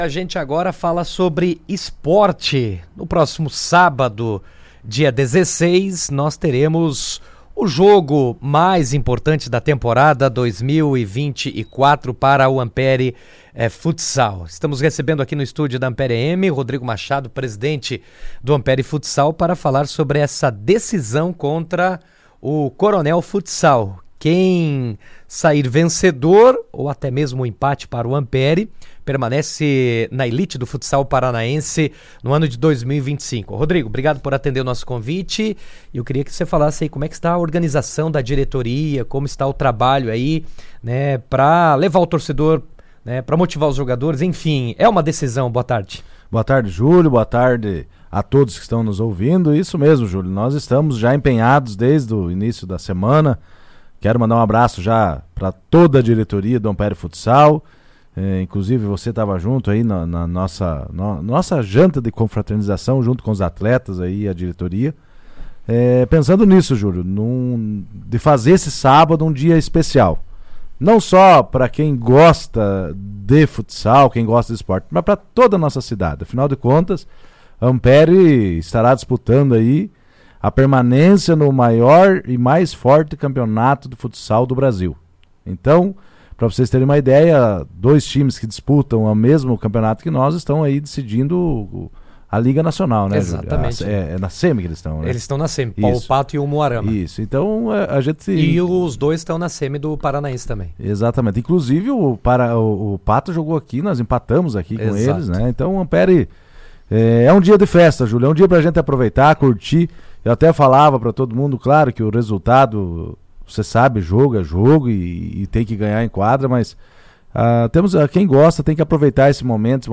Em entrevista ao Jornal RA 2ª Edição desta quarta-feira